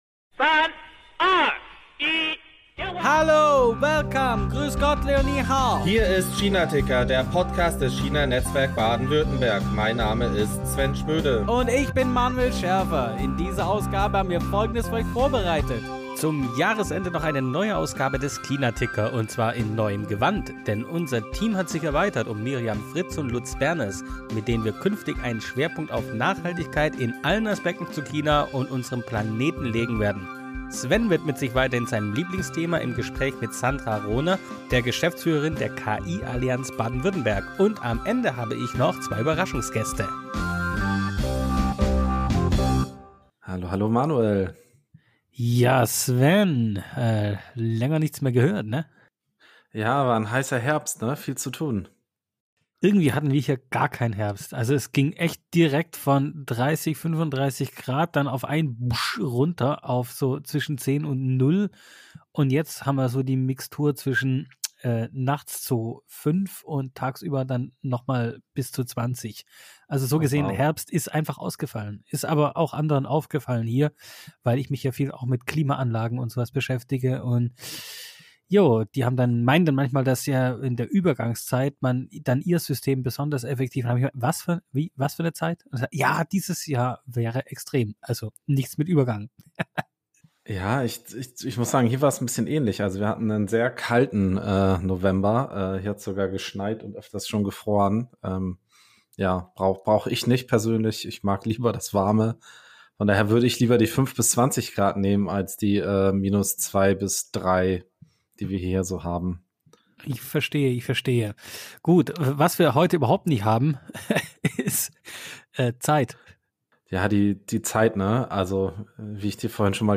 Und zum Schluss gibt’s ein Live-Gespräch mit dem China-Institut der deutschen Wirtschaft – plus wie immer unseren Veranstaltungskalender.